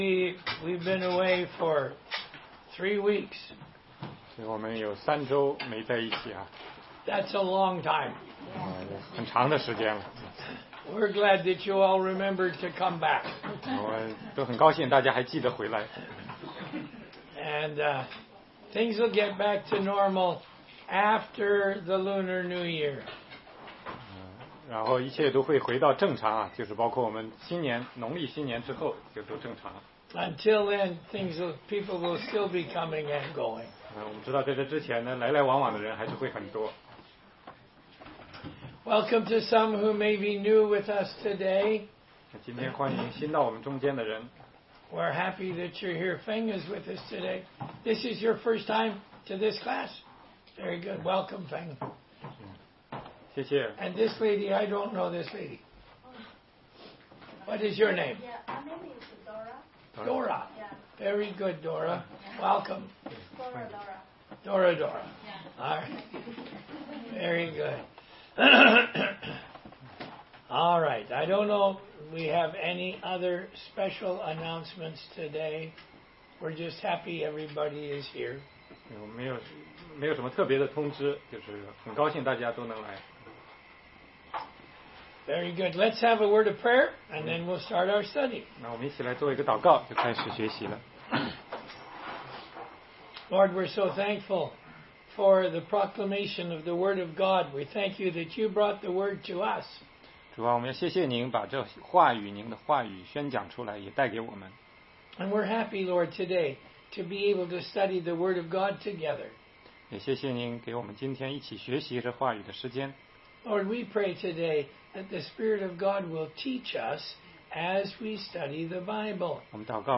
16街讲道录音 - 哥林多前书11章17-34节-按理领圣餐